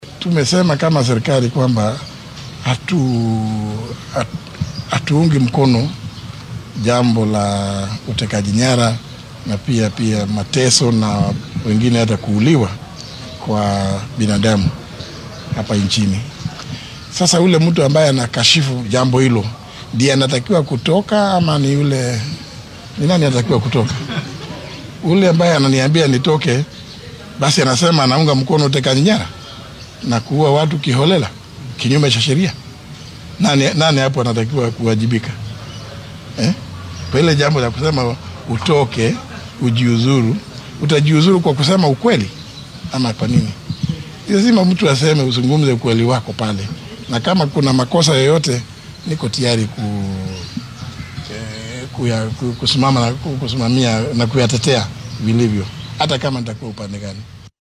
Waxaa uu madaxweynaha dalka William Ruto ka dalbaday inuu si cad kenyaanka ula wadaago cidda ka dambeysay falalka dilalka iyo afduubka ee dhacay. Xilli uu ku sugnaa ismaamulka Mombasa ayuu wasiir Muturi tilmaamay in siyaasadda dowladda ay tahay mid aan taageerayn afduubyada sidaasi awgeedna loo baahan yahay in dhibaatadan si cad looga hadlo.